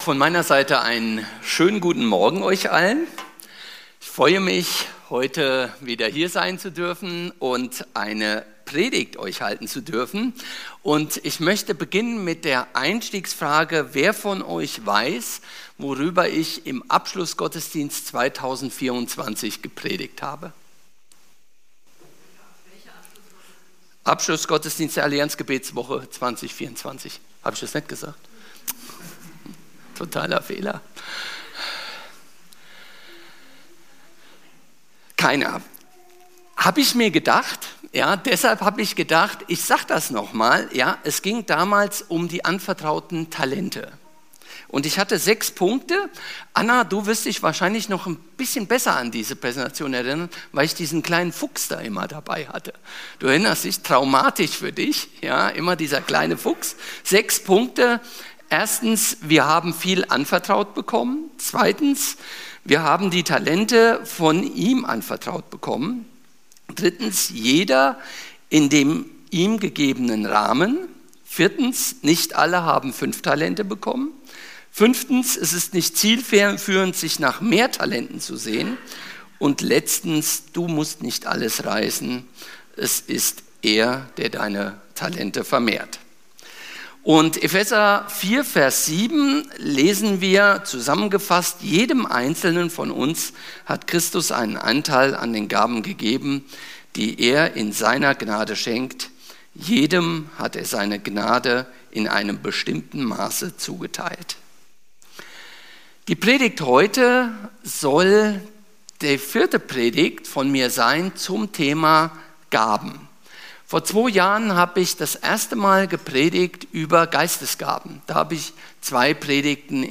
Predigten - FeG Steinbach